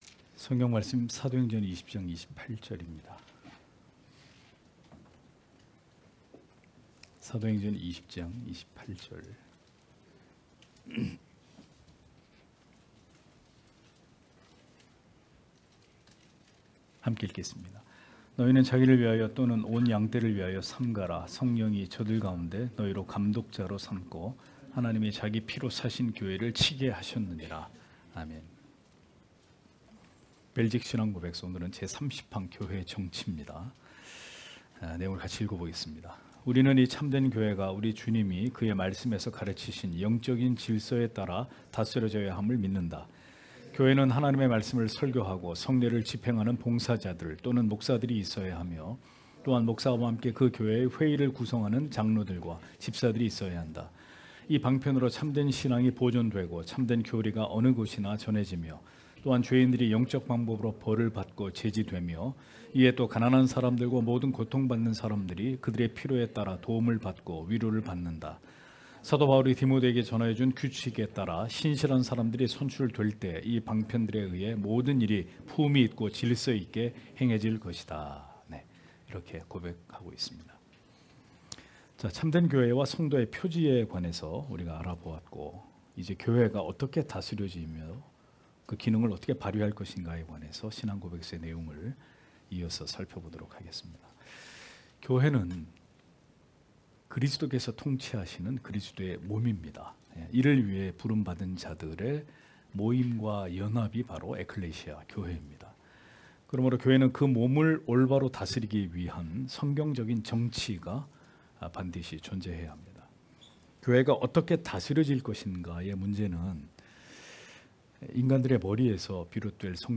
주일오후예배 - [벨직 신앙고백서 해설 35] 제30항 교회의 정치 (행 20장 28절)